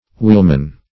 Search Result for " wheelman" : The Collaborative International Dictionary of English v.0.48: Wheelman \Wheel"man\, n.; pl. Wheelmen .